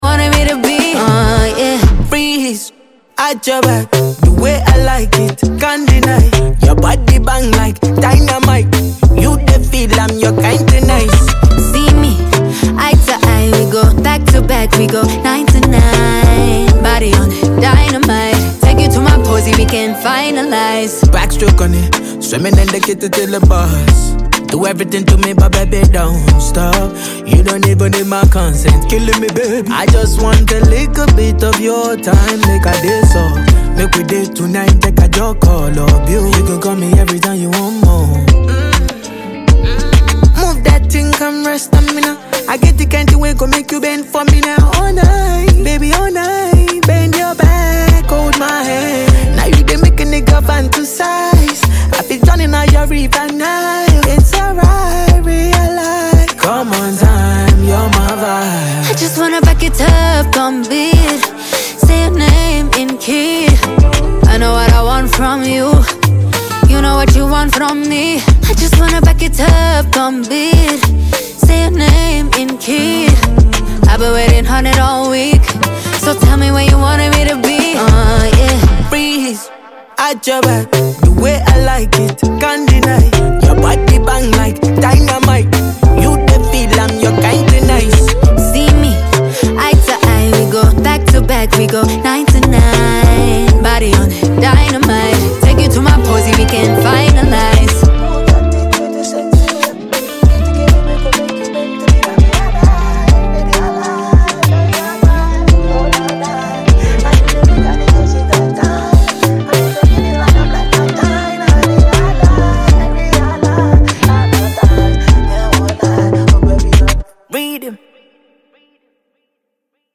filled with energy, melody and captivating sound